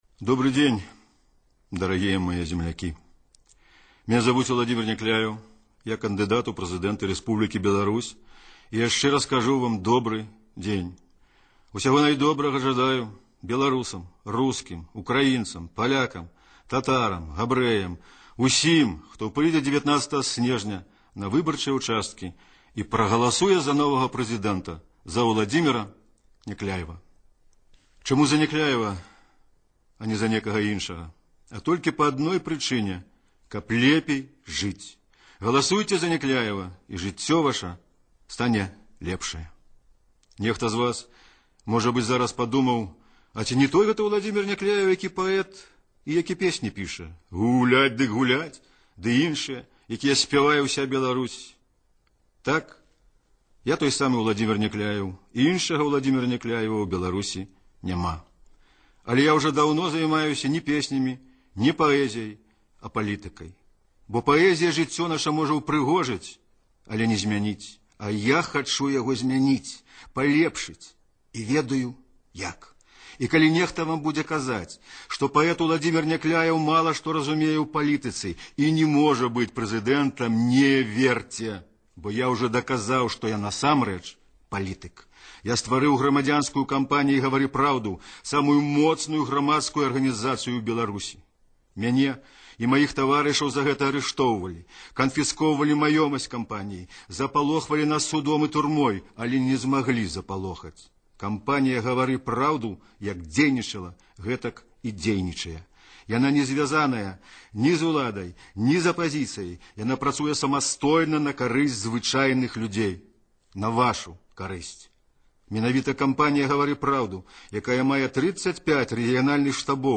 Радыёвыступ Уладзімера Някляева